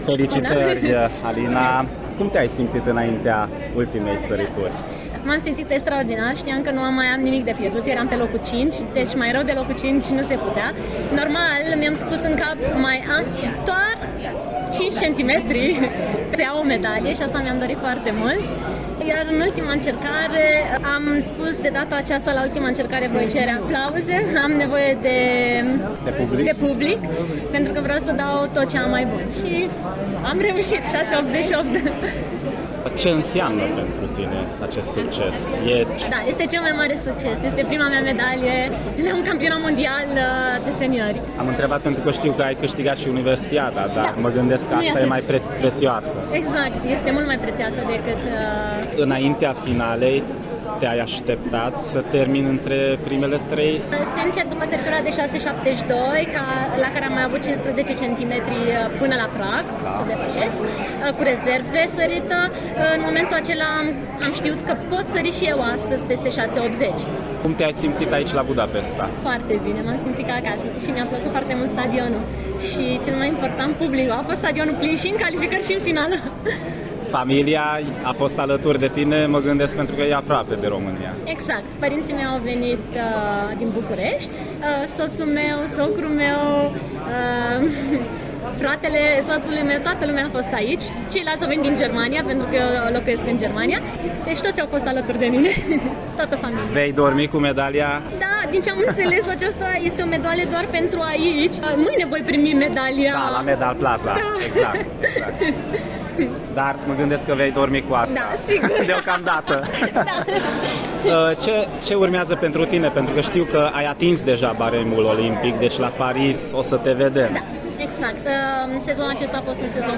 Interviu audio cu Alina Rotaru, medaliată cu bronz la Mondialele de atletism